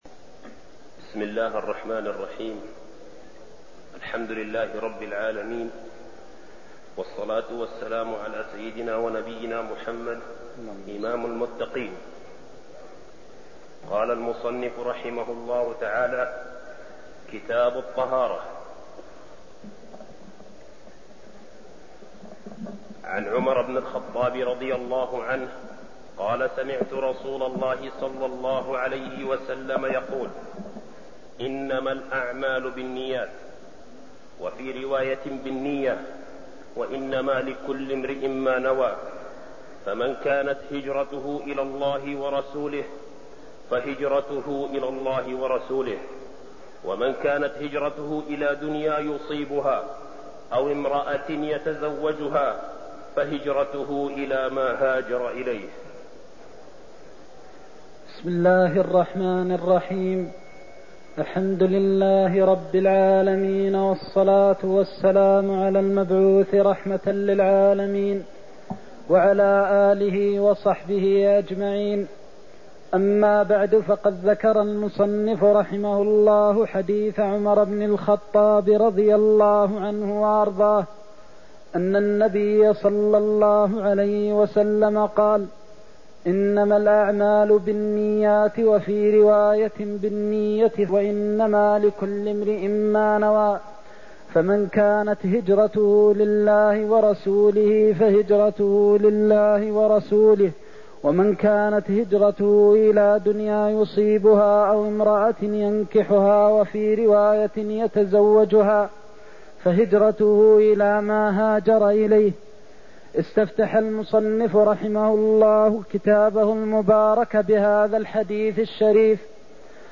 المكان: المسجد النبوي الشيخ: فضيلة الشيخ د. محمد بن محمد المختار فضيلة الشيخ د. محمد بن محمد المختار إنما الأعمال بالنيات (01)